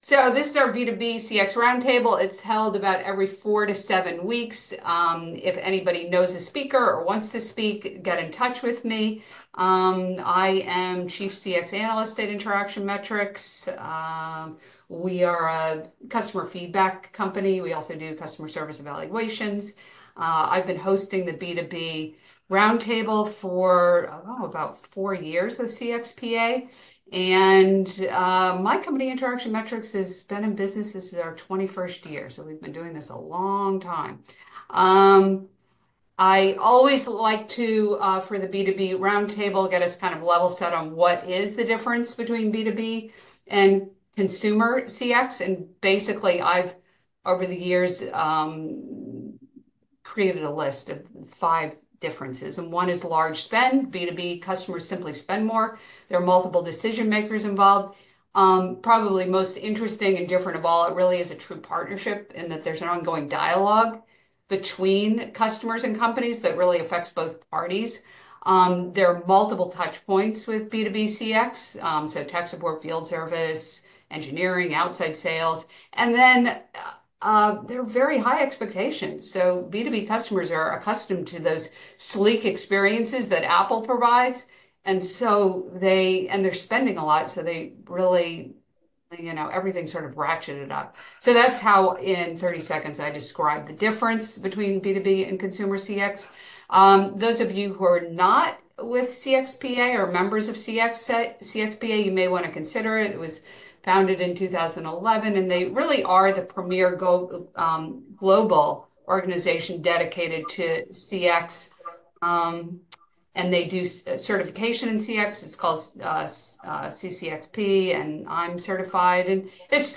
b2b-roundtable-ai-customer-experience-research-audio.mp3